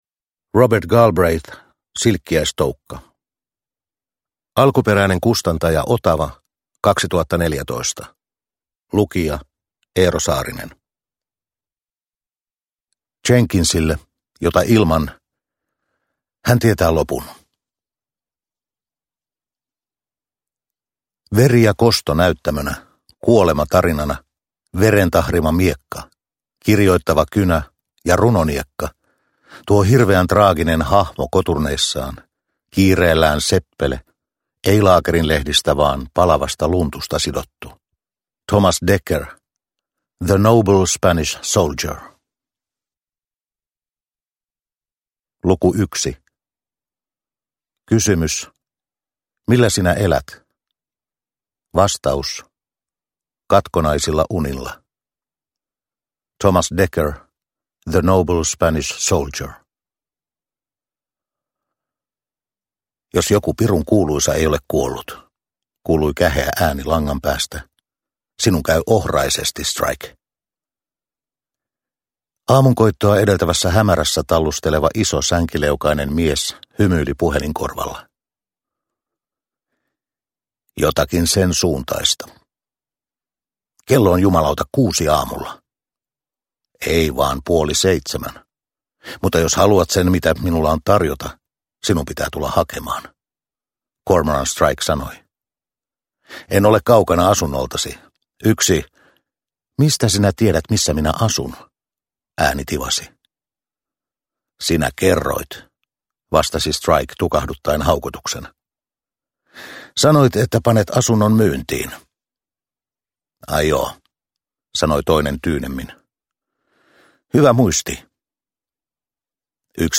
Silkkiäistoukka – Ljudbok – Laddas ner